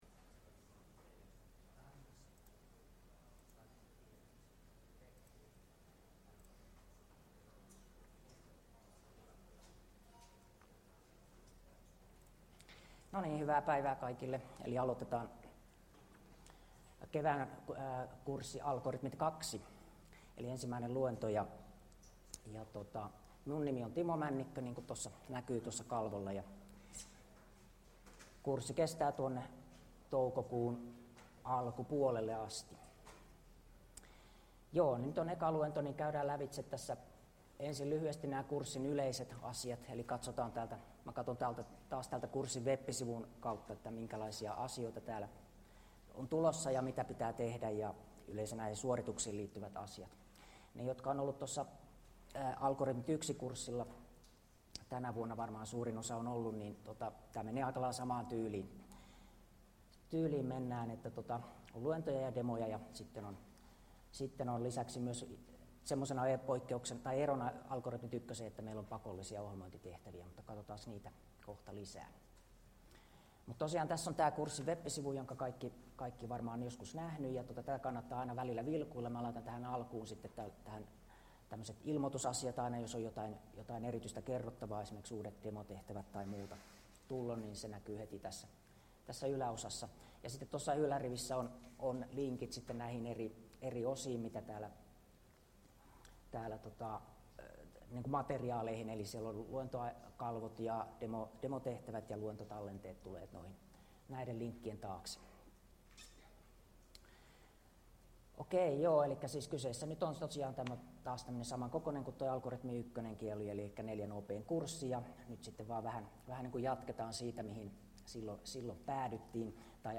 Luento 1 — Moniviestin